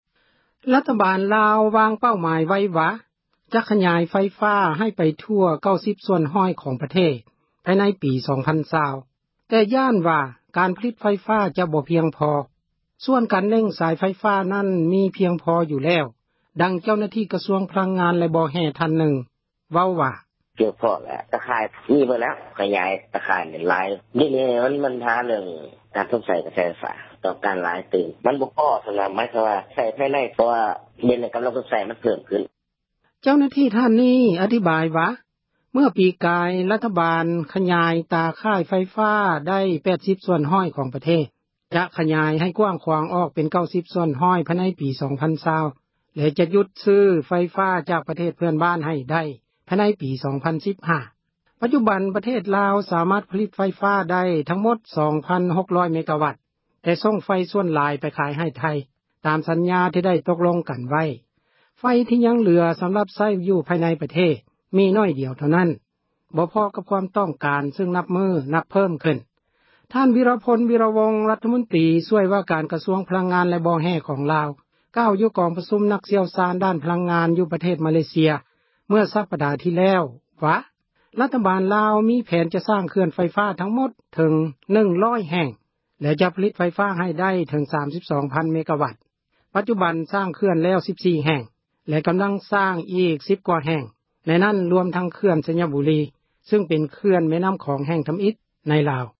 ຣັຖບານລາວ ວາງເປົ້າໝາຍ ໄວ້ວ່າ ຈະຂຍາຍ ໄຟຟ້າ ໄປ ທົ່ວປະເທດ ໃຫ້ໄດ້ 90 ສ່ວນຮ້ອຍ ພາຍໃນປີ 2020. ແຕ່ບັນຫາ ມີຢູ່ວ່າ ການຜລິດ ໄຟຟ້າ ຍັງບໍ່ ພຽງພໍ ສ່ວນການ ເຫນັ່ງສາຍ ໄຟຟ້ານັ້ນ ມີພຽງພໍ ຢູ່ແລ້ວ. ດັ່ງເຈົ້າໜ້າທີ່ ກະຊວງ ພະລັງງານ ແລະ ບໍ່ແຮ່ ທ່ານນຶ່ງ ເວົ້າວ່າ: